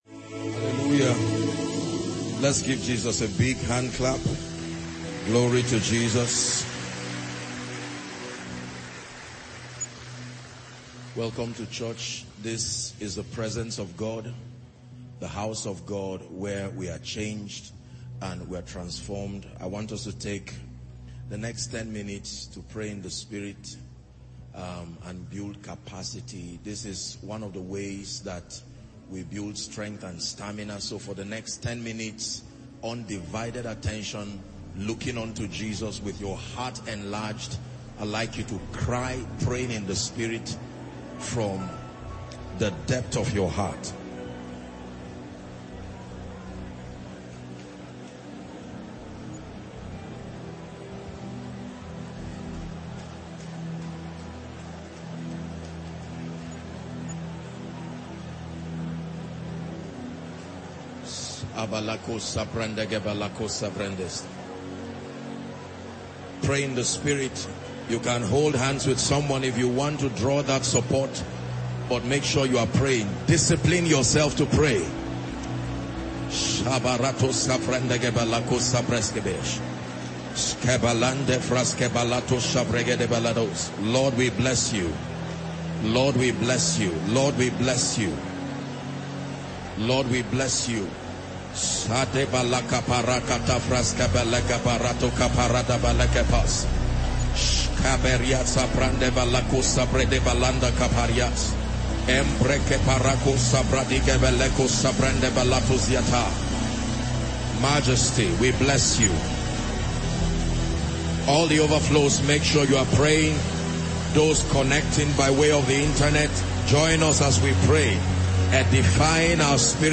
Sermon Title: THE SEEING EYES (Accessing the gift of sight)– Text: Matthew 13:13-16